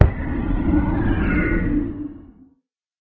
elder_death.ogg